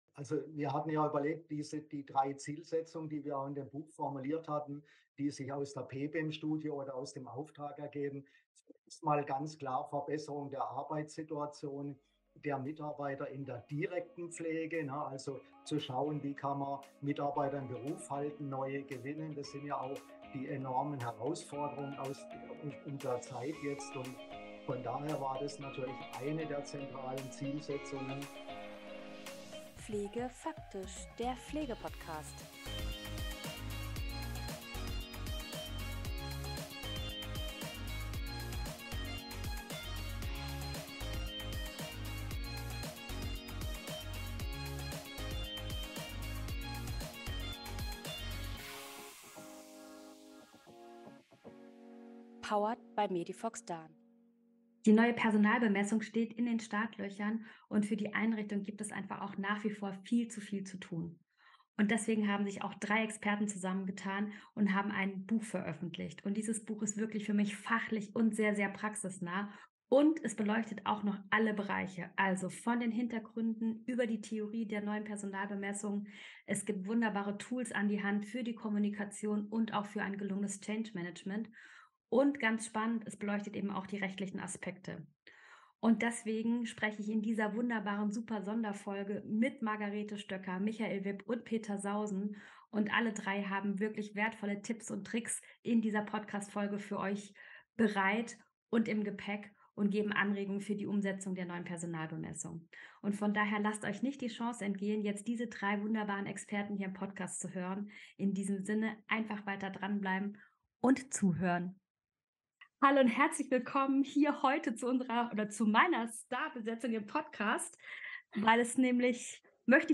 Podcast-Interview: Die neue Personalbemessung (PeBeM)